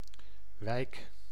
Ääntäminen
Synonyymit buurt gracht stadswijk stadsdeel Ääntäminen : IPA: [ʋɛjk] Haettu sana löytyi näillä lähdekielillä: hollanti Käännös Ääninäyte Substantiivit 1. neighborhood US US 2. district US 3. quarter US Suku: f .